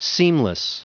Prononciation du mot seamless en anglais (fichier audio)
Prononciation du mot : seamless